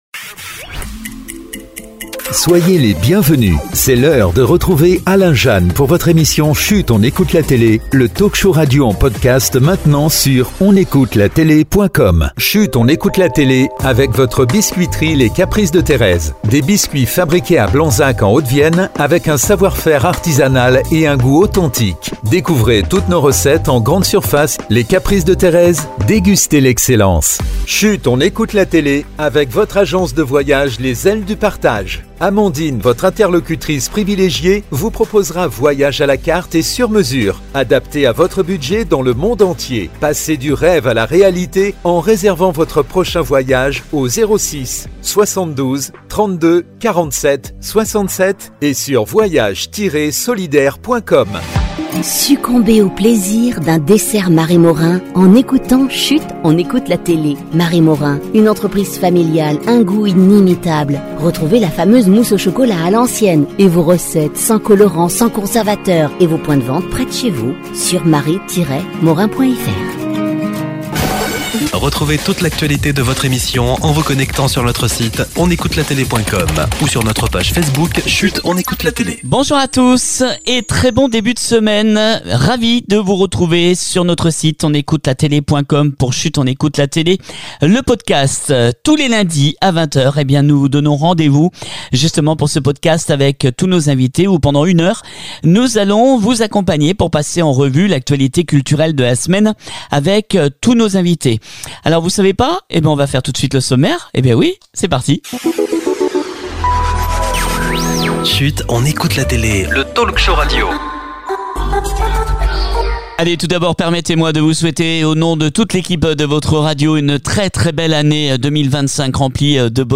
On se retrouve ce lundi 6 Janvier 2025 pour la première grande hebdo de l’année 2025 de Chut on écoute la télé avec de nombreux invités, on parle de